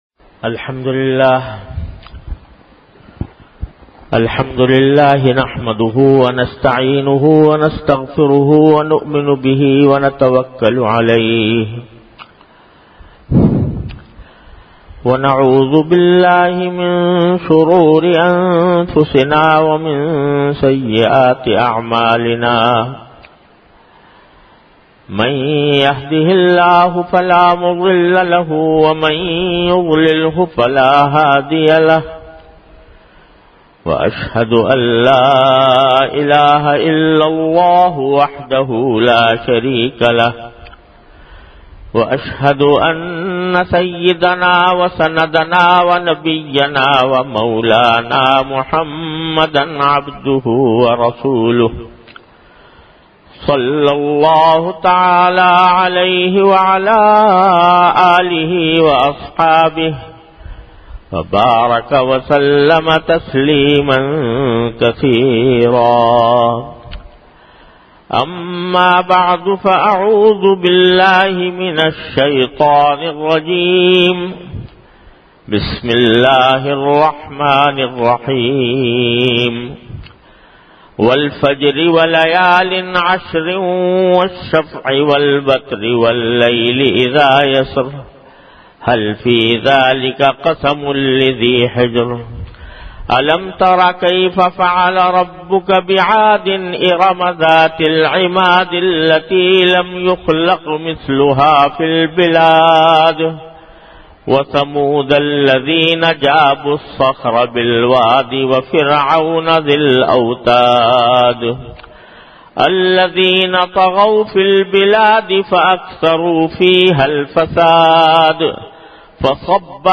An Islamic audio bayan by Hazrat Mufti Muhammad Taqi Usmani Sahab (Db) on Tafseer. Delivered at Jamia Masjid Bait-ul-Mukkaram, Karachi.